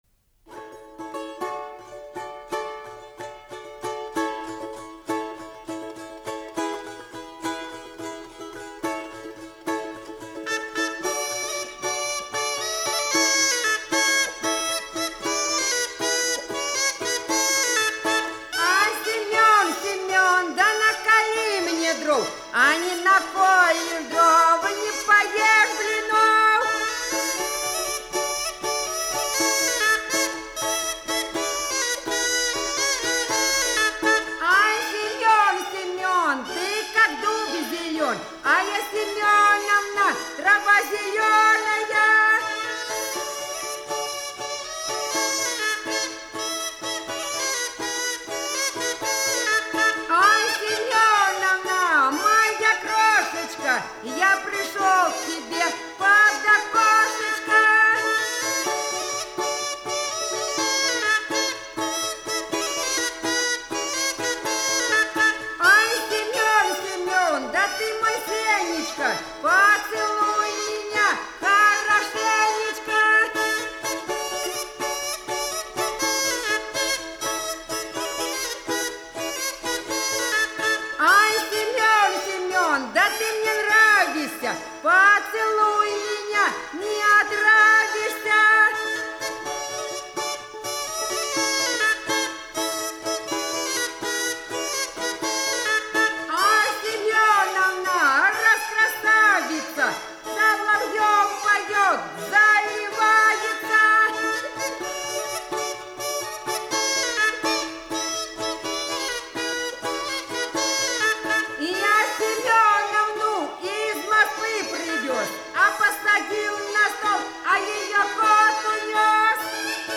Голоса уходящего века (Курское село Илёк) Семёновна (балалайки, рожок, пение, частушки)